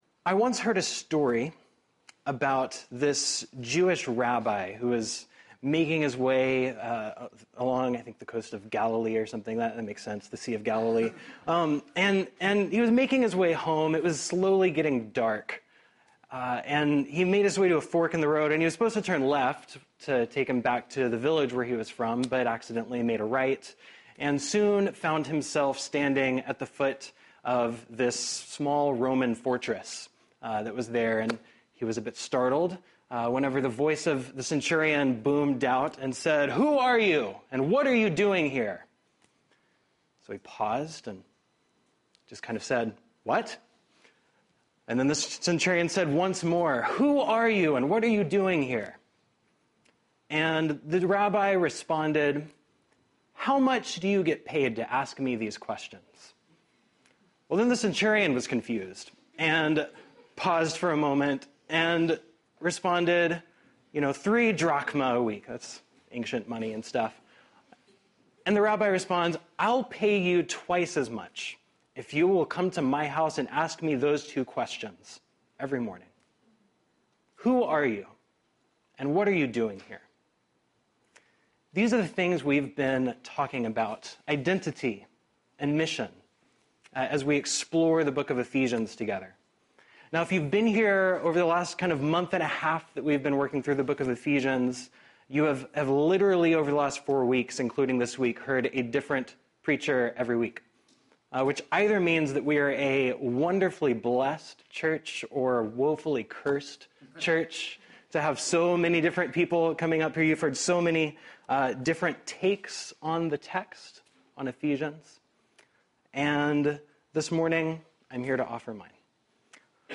In the spring of 2018, I contributed to a sermon series in the book of Ephesians at Sanctuary Church.